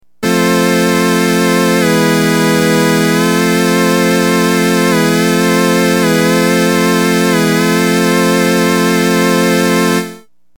Sounds (These were all done on my Lowrey T2)
Glide:  Self-explanatory.  Vibrato is On, Fast, Heavy.  You can hear it all but eliminated when Glide is pressed.